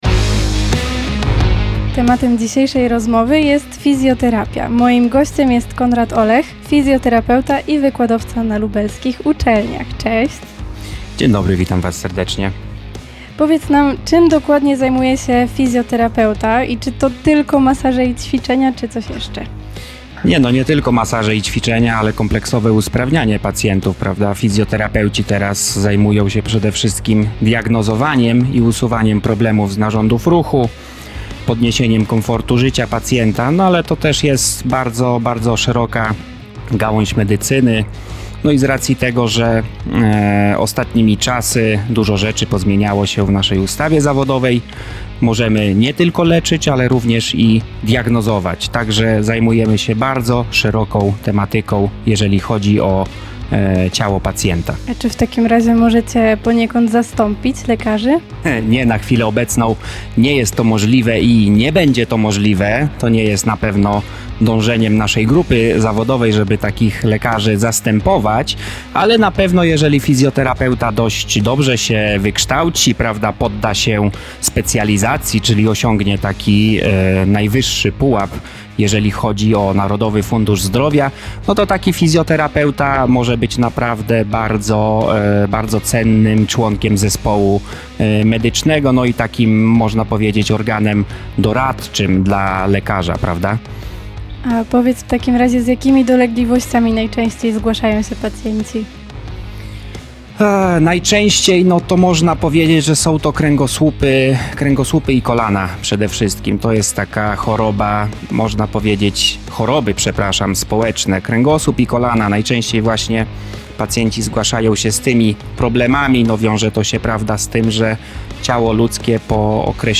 Opublikowano w Aktualności, Audycje, Sport